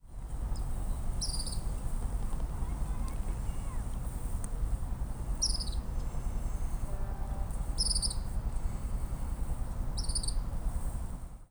Tropical Kingbird
Tyrannus melancholicus